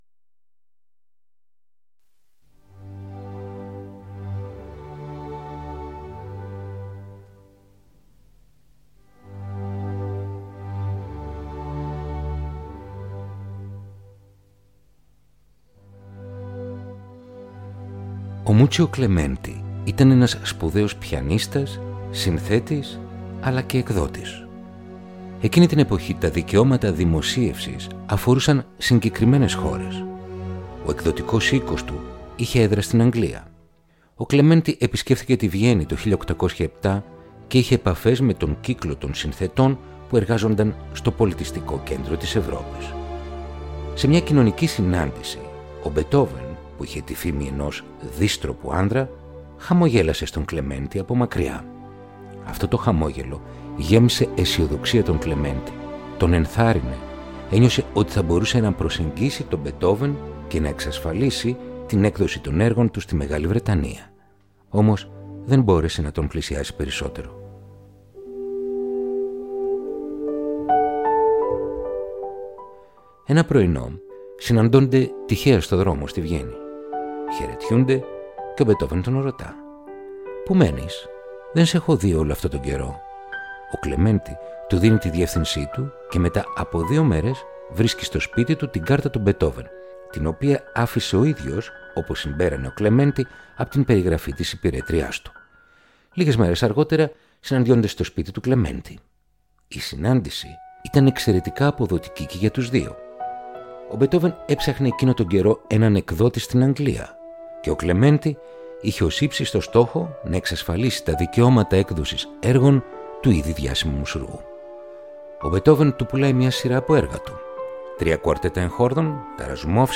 Κοντσέρτα για πιάνο του Beethoven – Επεισόδιο 6ο
Ludwig van Beethoven Κοντσέρτο για Πιάνο σε Ρε Μείζονα